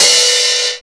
RX OHH.wav